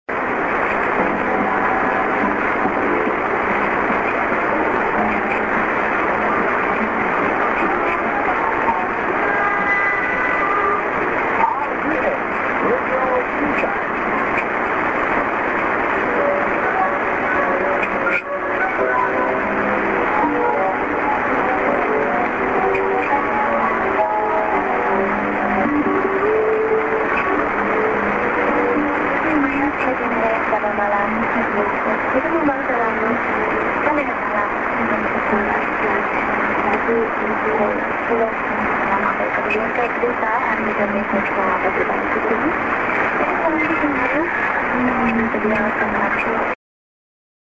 ->ID:man:RTM Radio ????(man)->ANN(women:??)